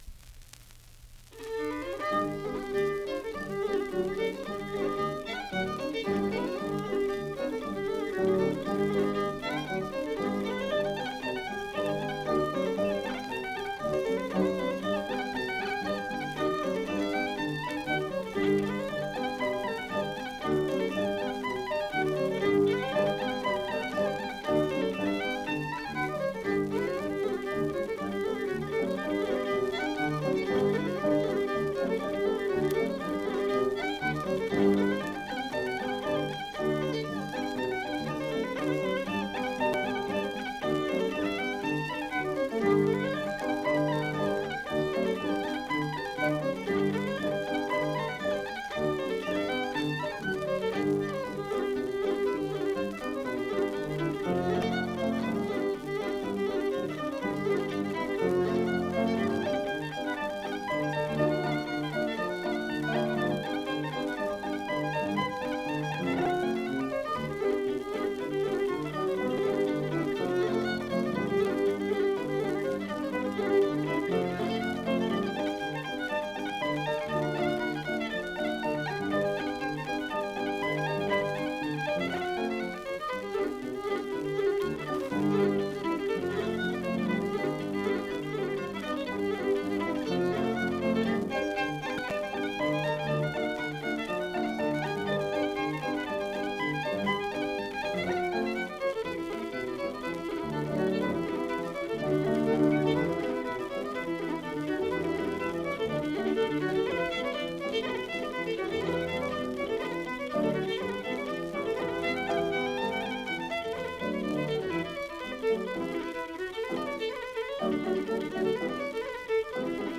Keywords: Irish Traditional Music
Fiddle, with guitar accompaniment. digitised from an original 78RPM shellac record